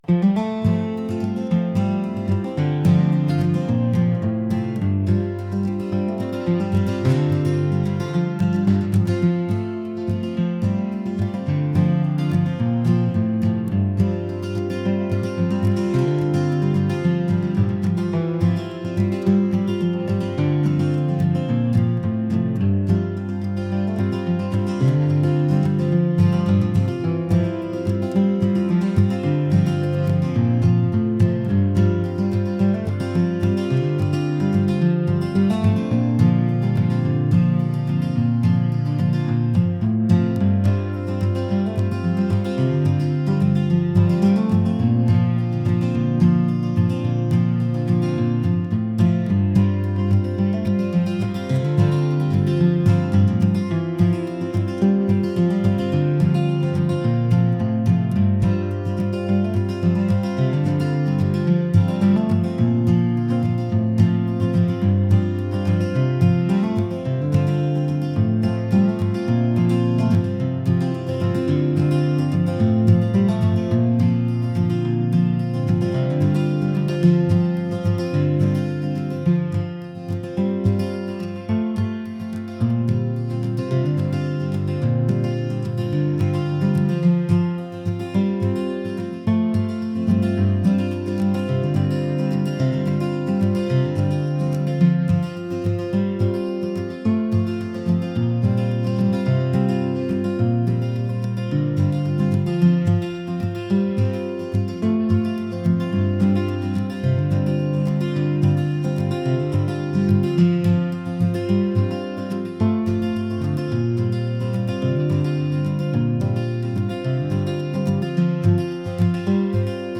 folk | acoustic